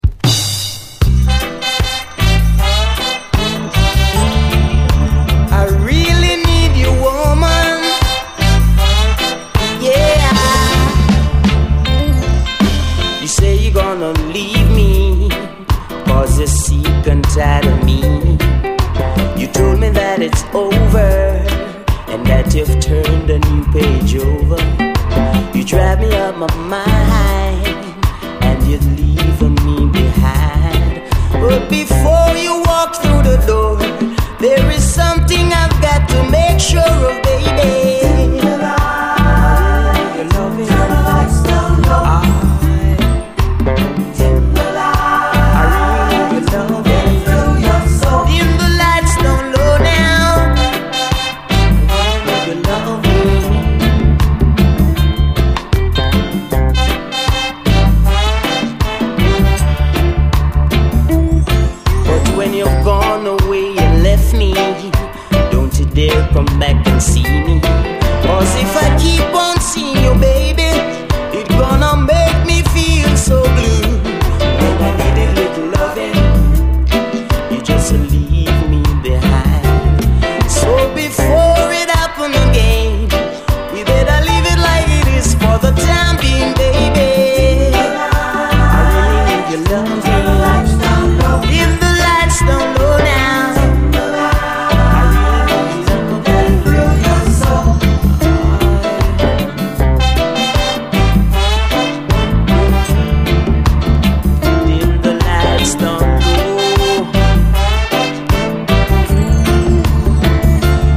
REGGAE
ソウルフルなこみ上げ系メロディーが最高！
後半はダブ！